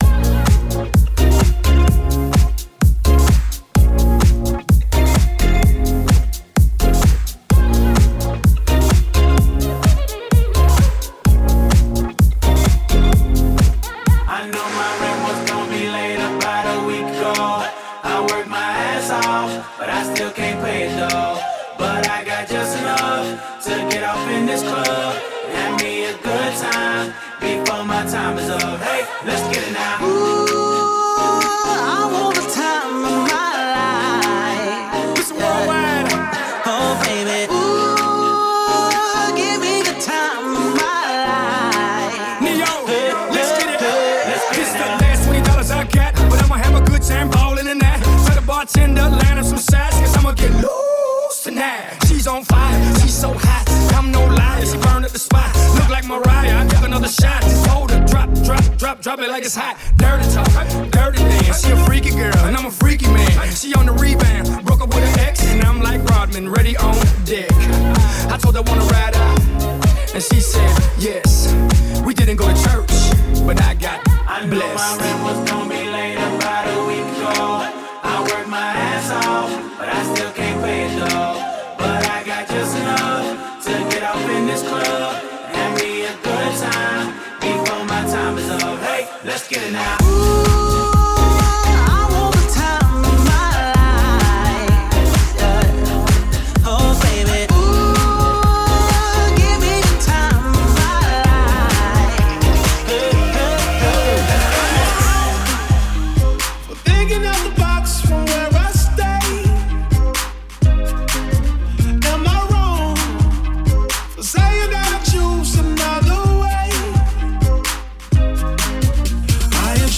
exclusive open format mix